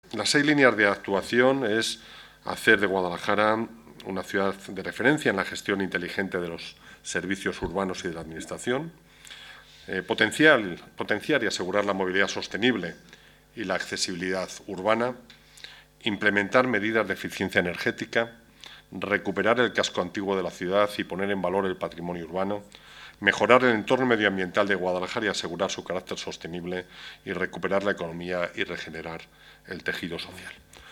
Declaraciones de Antonio Román sobre el Desarrollo Urbano Sostenible Integrado